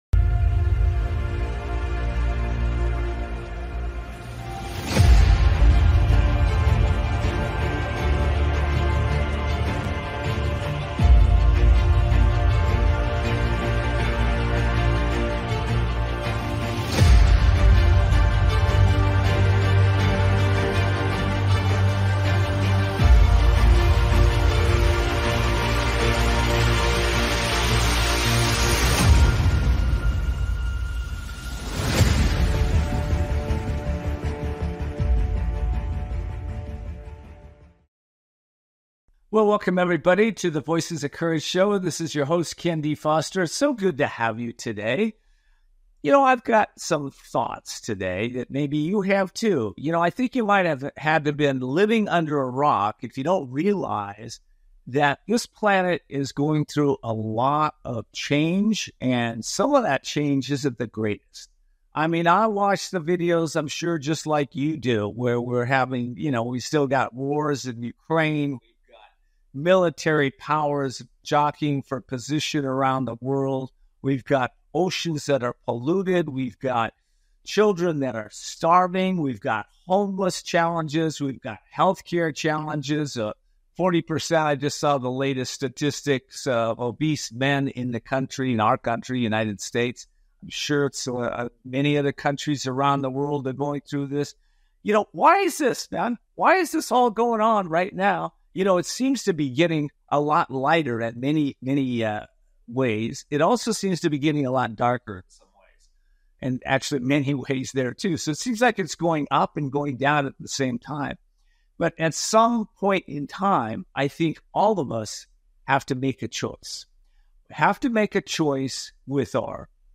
Guest, Neale Donald Walsch, The Courage to Awaken Humanity